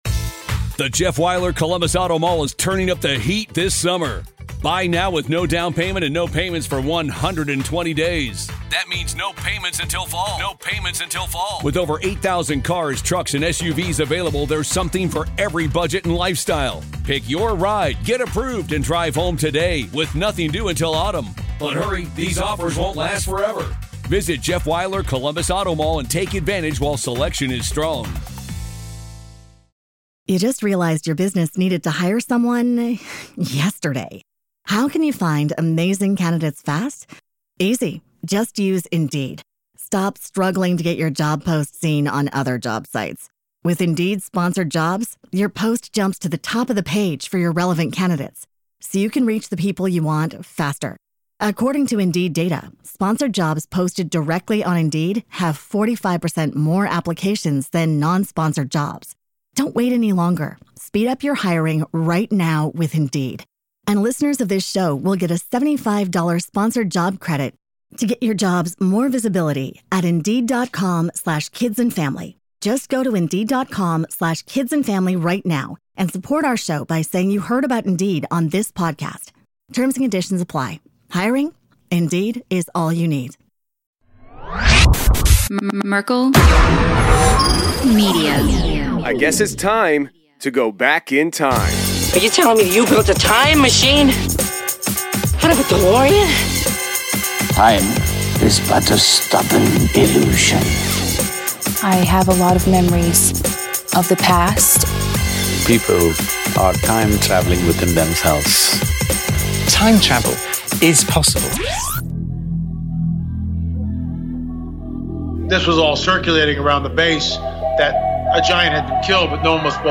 entertaining conversation